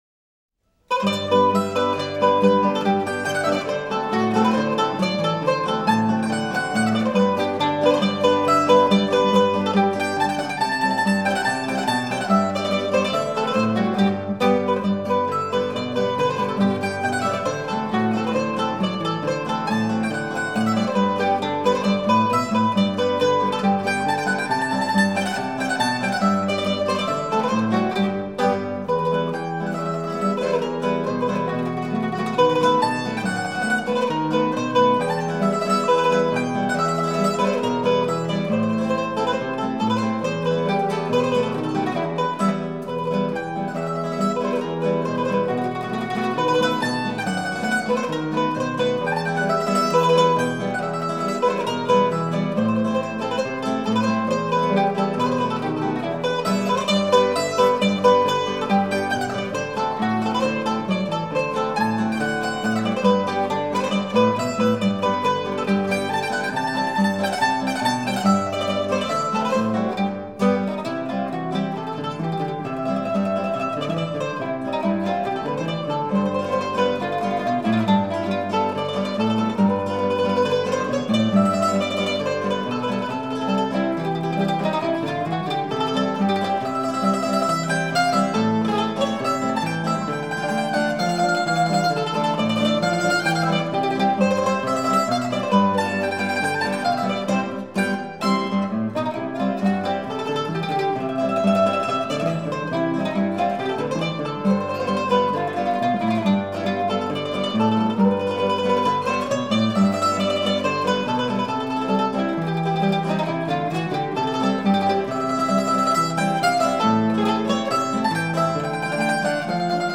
Duo di Barbengo: Polca brillante
Ticino. Trad., arr. Duo di Barbengo